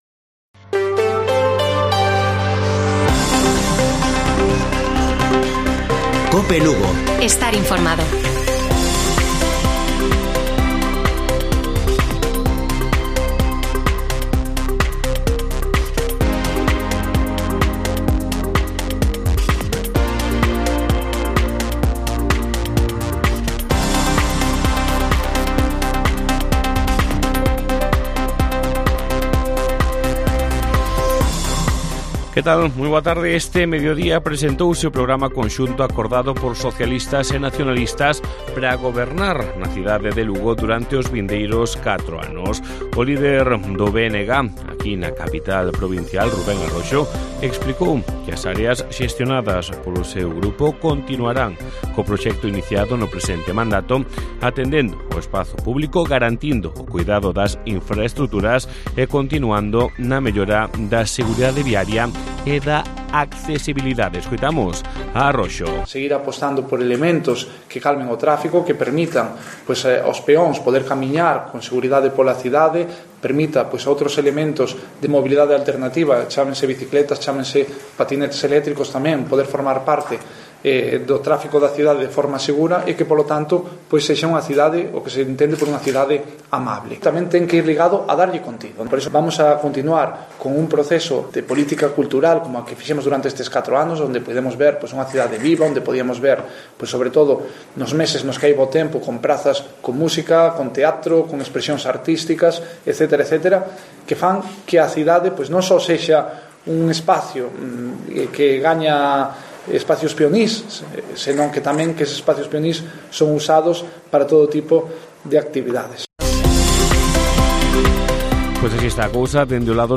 Informativo Mediodía de Cope Lugo. 15 DE JUNIO. 14:20 horas